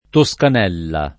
vai all'elenco alfabetico delle voci ingrandisci il carattere 100% rimpicciolisci il carattere stampa invia tramite posta elettronica codividi su Facebook Toscanella [ to S kan $ lla ] top. — nome di Tuscania (Lazio) dal Medioevo al 1911; e nome, tuttora, di una borgata sulla Via Emilia presso Imola (E.-R.) — anche cognome